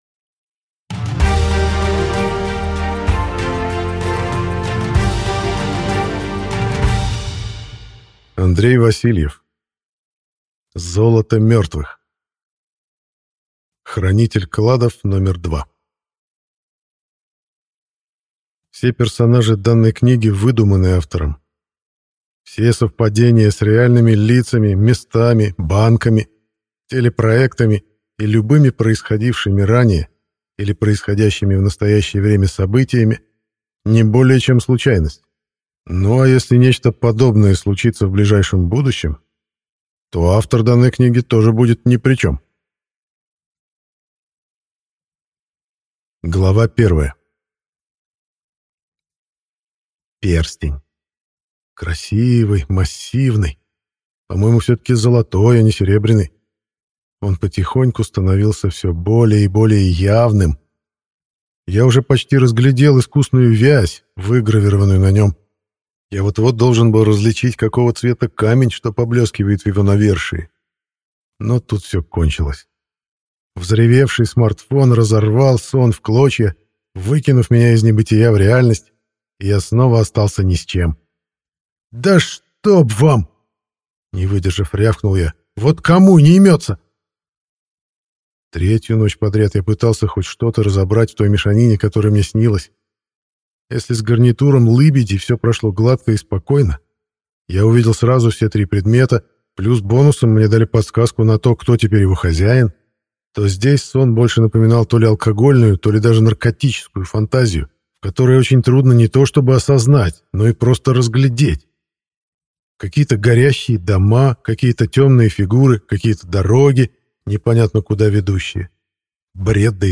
Прослушать фрагмент аудиокниги Золото мертвых Андрей Васильев Произведений: 41 Скачать бесплатно книгу Скачать в MP3 Вы скачиваете фрагмент книги, предоставленный издательством